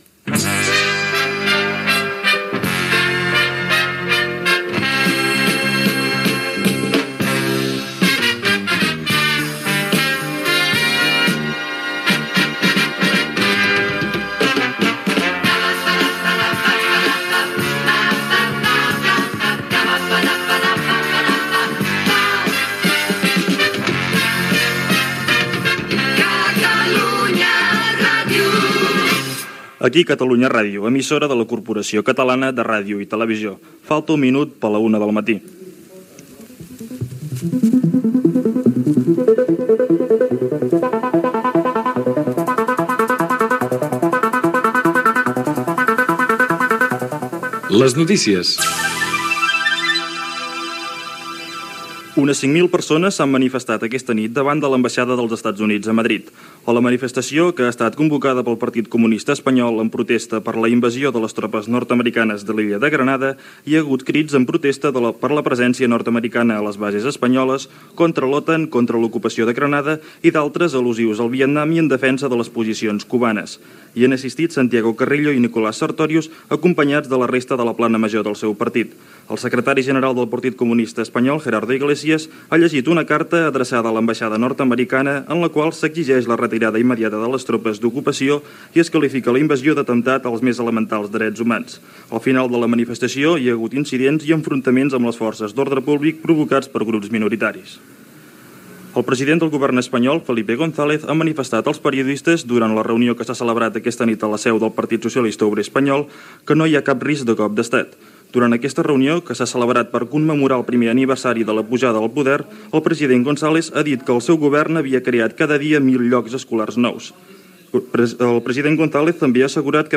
Indicatiu de l'emissora, hora, careta del programa (veu Miquel Calçada), manifestació del Partido Comunista Español a Madrid, declaracions del president del govern espanyol Felipe González, reunió del Grupo de Contadora, esports, careta de sortida i indicatiu
Informatiu